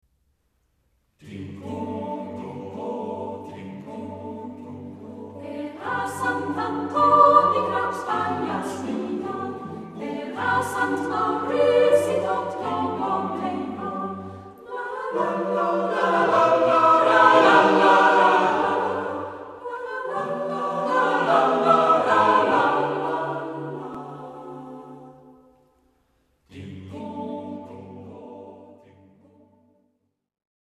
SATB (4 voices mixed) ; Full score.
Tonality: G major